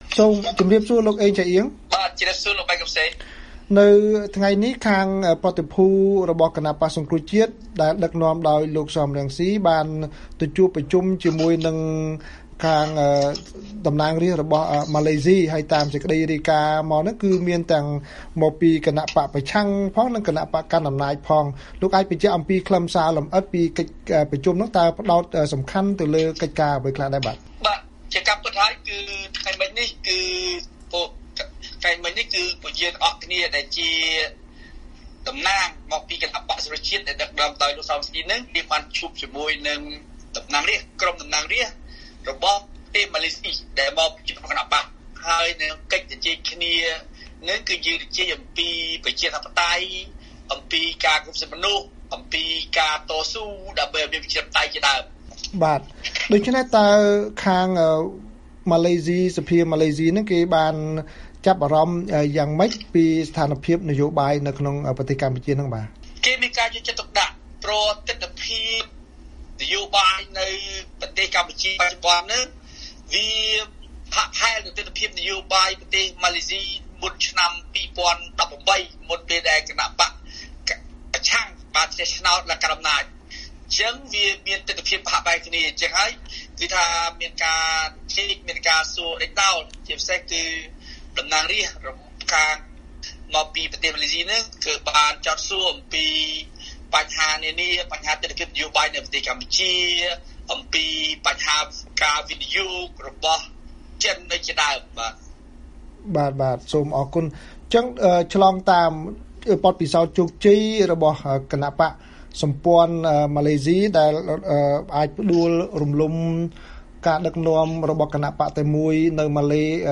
បទសម្ភាសន៍ VOA៖ បក្សសង្គ្រោះជាតិពង្រឹងមិត្តភាពជាមួយអ្នកតំណាងរាស្ត្រម៉ាឡេស៊ី